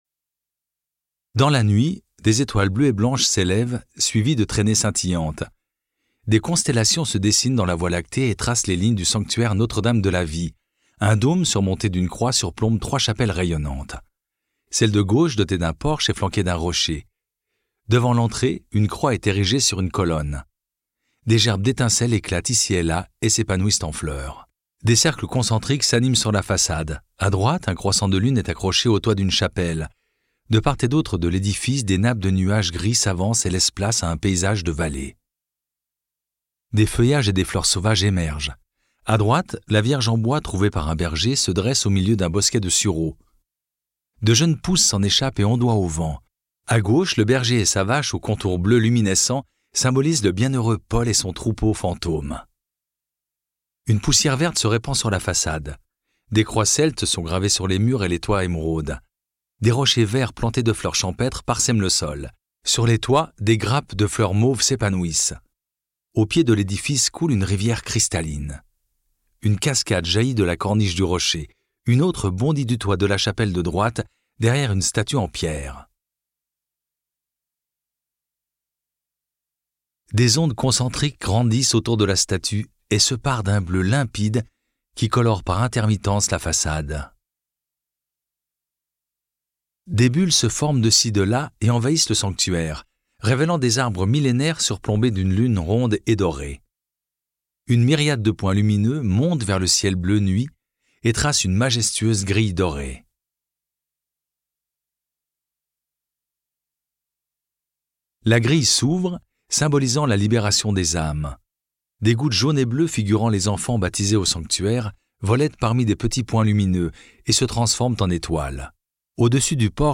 FRDL - audiodescription Saint-Martin-de-Belleville.mp3